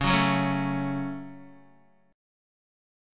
C#sus4#5 Chord